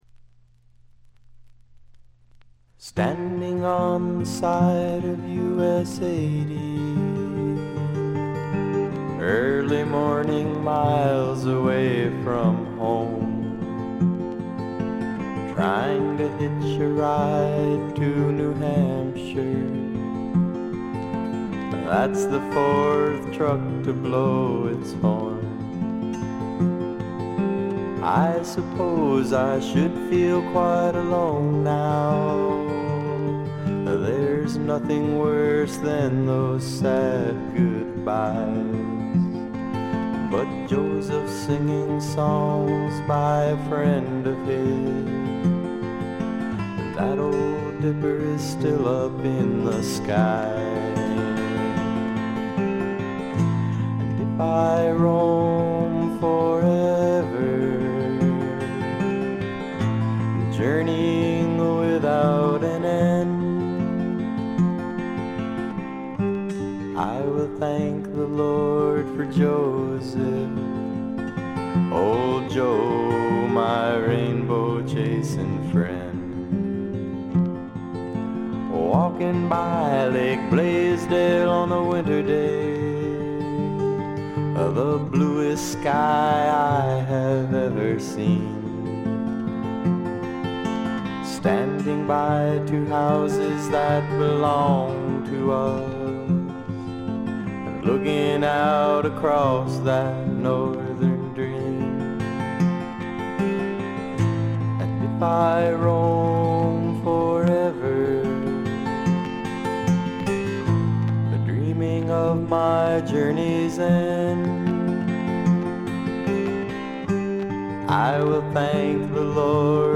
軽微なバックグラウンドノイズ、チリプチ少し。
試聴曲は現品からの取り込み音源です。